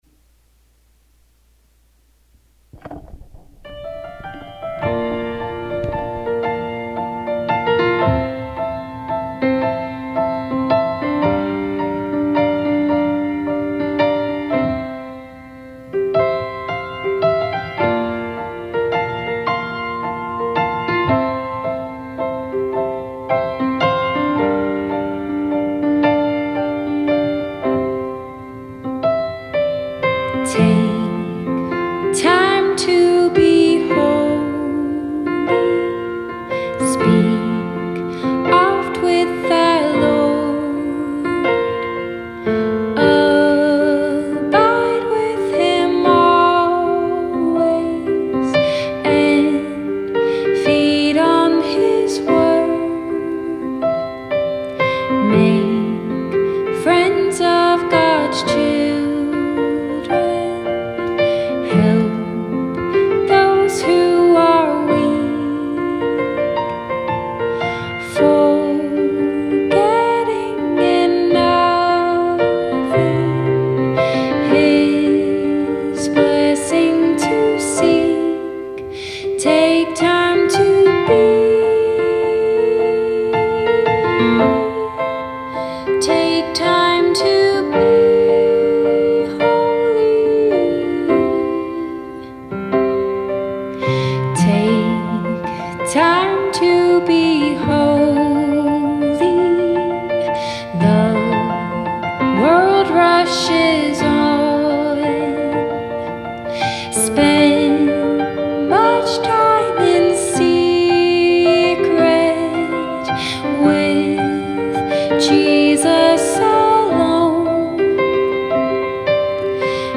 Song: Take Time to Be Holy